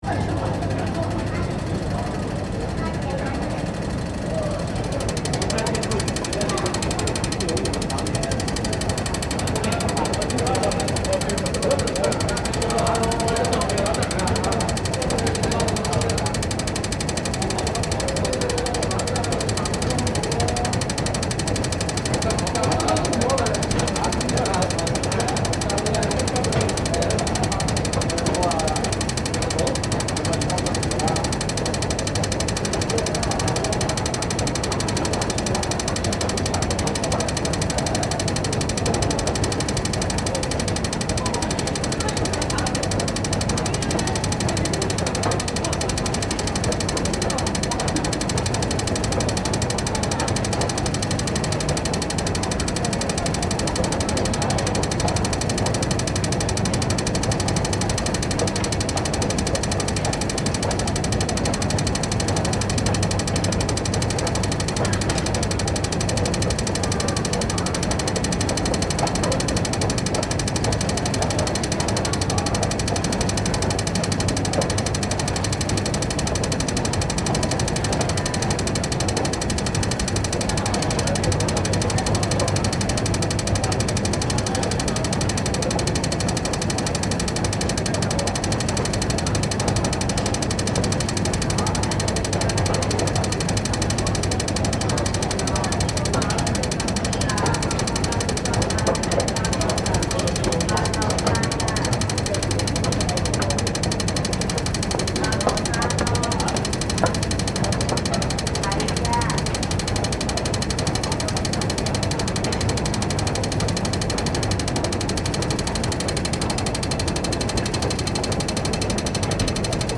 Sound recorded in Beijing, Shanghai, Hong Kong and Macao.
- Tick sounds, Hong Kong metro (6:11)
hong_kong_metro_ticks.mp3